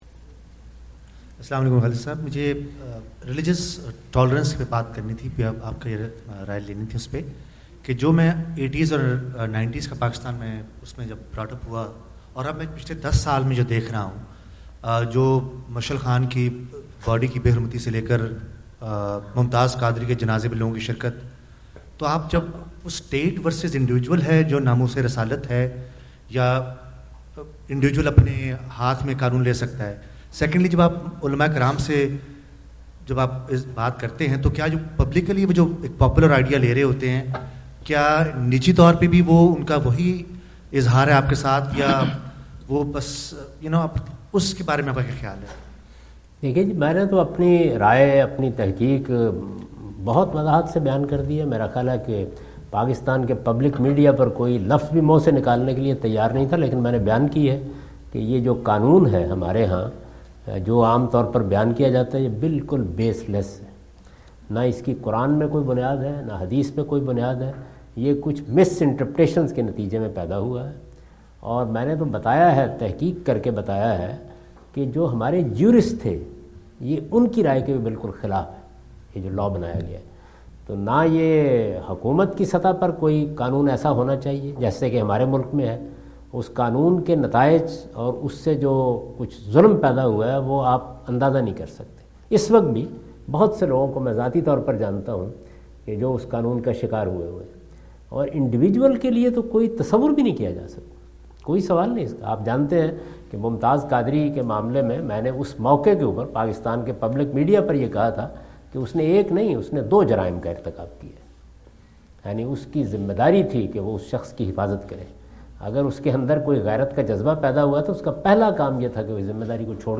Category: Foreign Tours / USA 2017 / Questions_Answers /
Javed Ahmad Ghamidi answer the question about "Taking Law in Hand in Religious Matters" During his US visit in Dallas on October 08,2017.
جاوید احمد غامدی اپنے دورہ امریکہ2017 کے دوران ڈیلس میں "مذہبی معاملات میں قانون ھاتھ میں لینا" سے متعلق ایک سوال کا جواب دے رہے ہیں۔